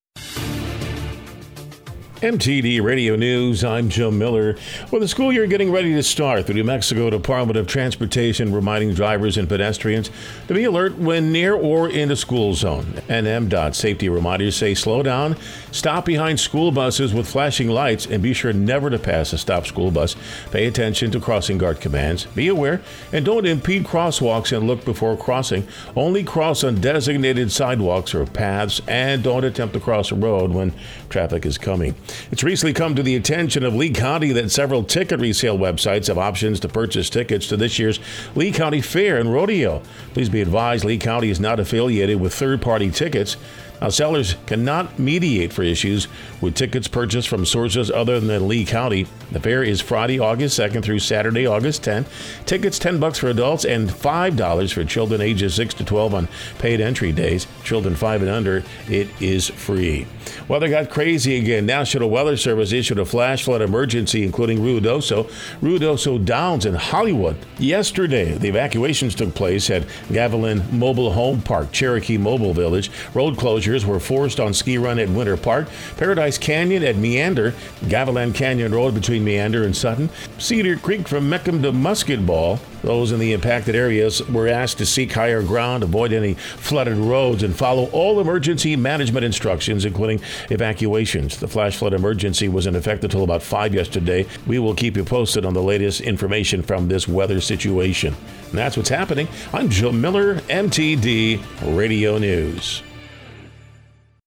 W105 NEWS SE NEW MEXICO AND WEST TEXAS